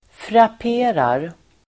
Ladda ner uttalet
Uttal: [frap'e:rar]